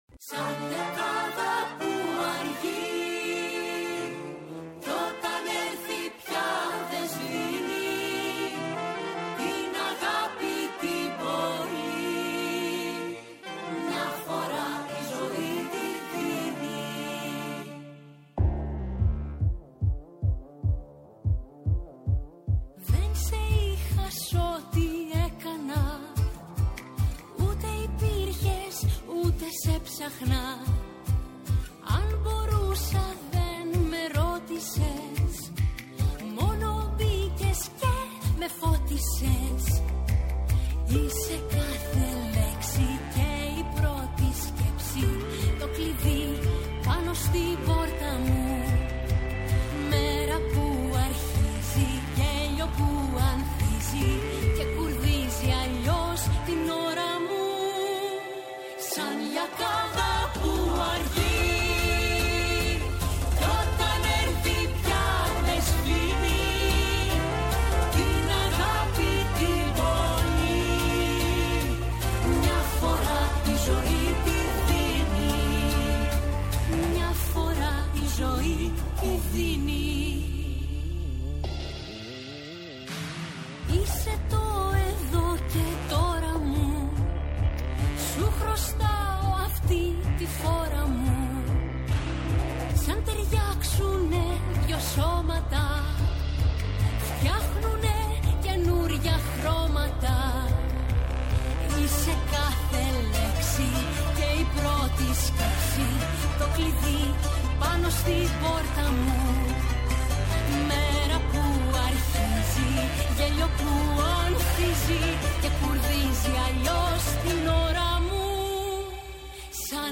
έρχεται ζωντανά στο στούντιο του Δεύτερου Προγράμματος